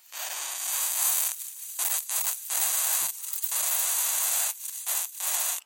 描述：电气嗡嗡声，嘶嘶声，传输中断等 为高级音响2017录制的科幻音效。
Tag: 电声 嘶嘶声 传输 干扰 电力